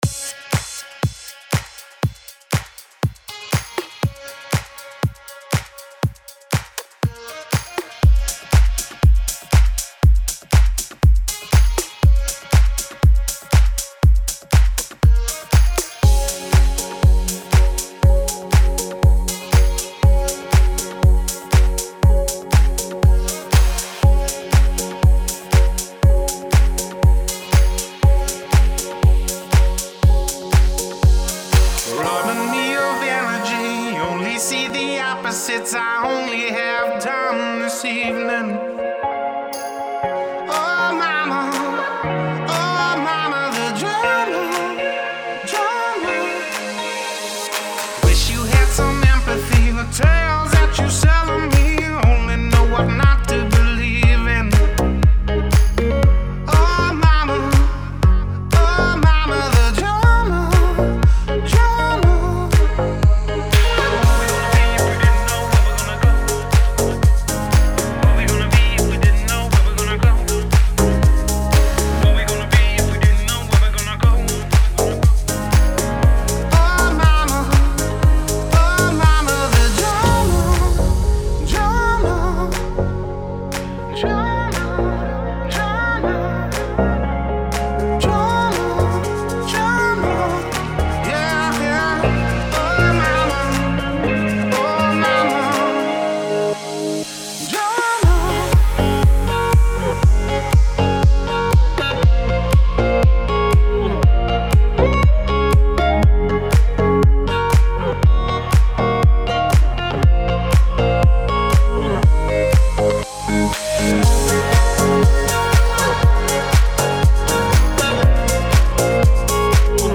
Style: Future House, EDM, Up-tempo